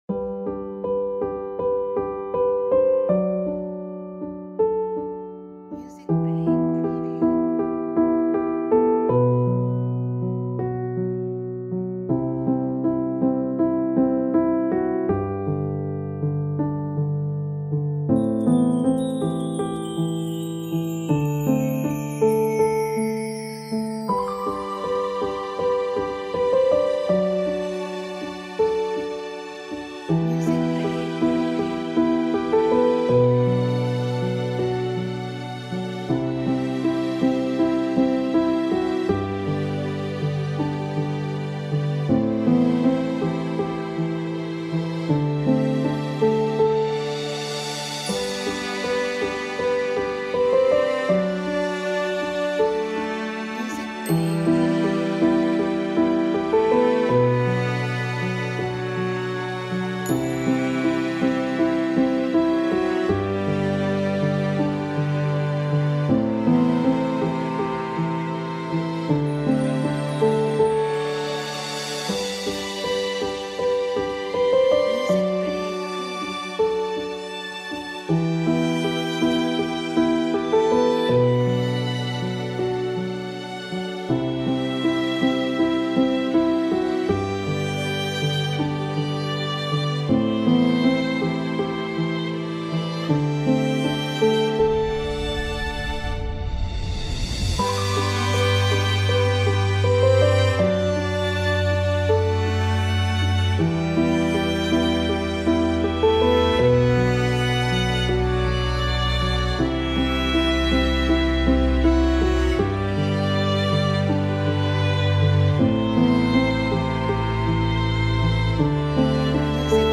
Background music for video.